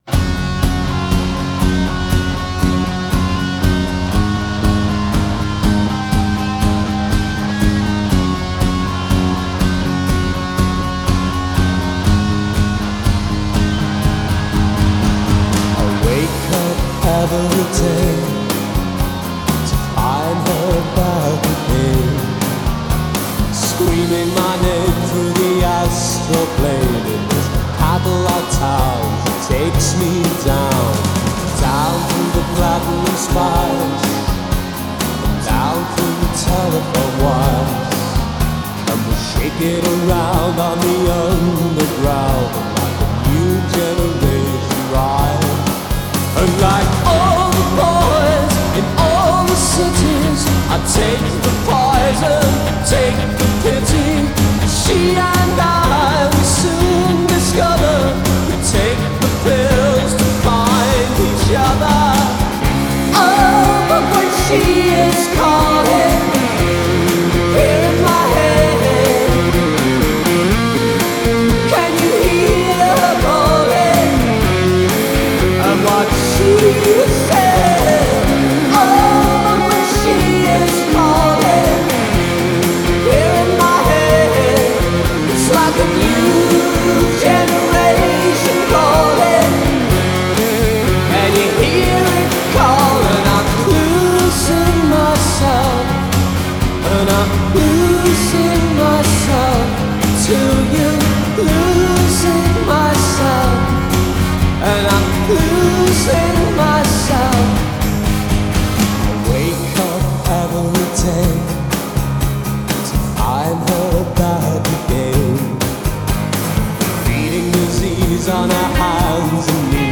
Style: Alt Rock